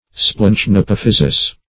Search Result for " splanchnapophysis" : The Collaborative International Dictionary of English v.0.48: Splanchnapophysis \Splanch`napoph"y*sis\, n.; pl.